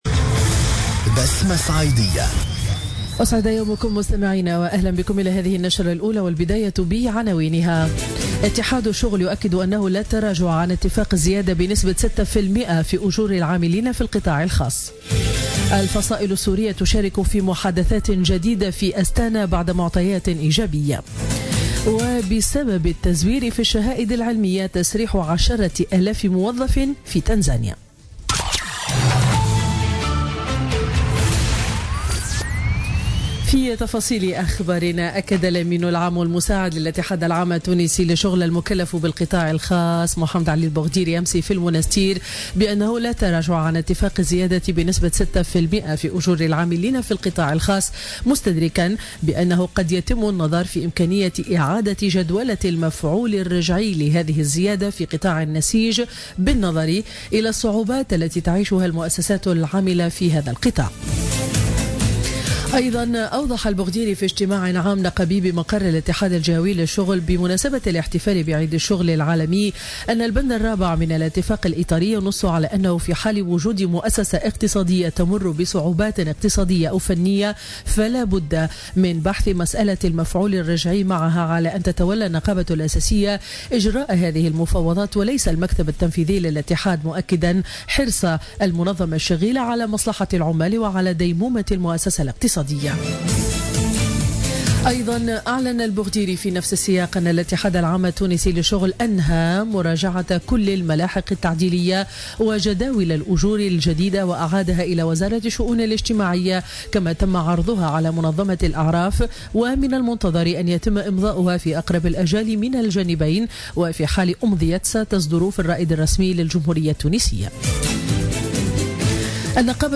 نشرة أخبار السابعة صباحا ليوم الأحد 30 أفريل 2017